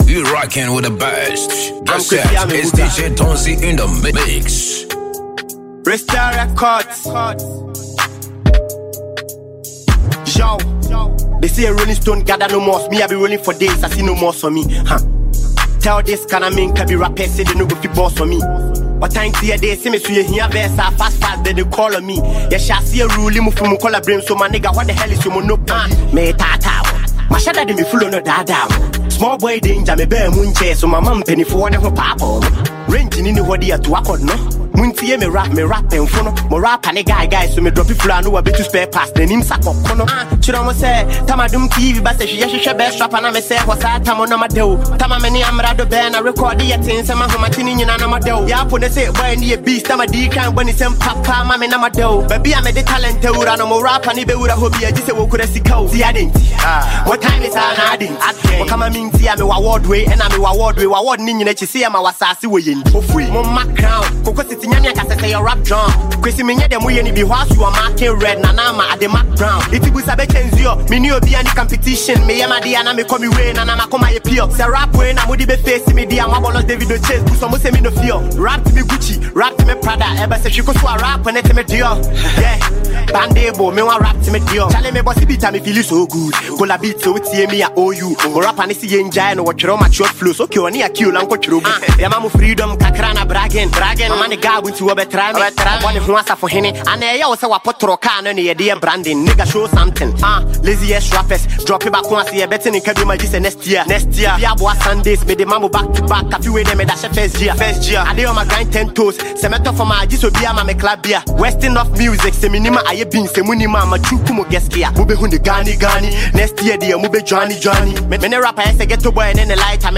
vibrant mixtape